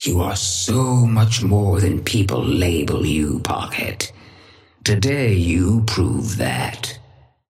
Sapphire Flame voice line - You are so much more than people label you, Pocket. Today, you prove that.
Patron_female_ally_synth_start_05.mp3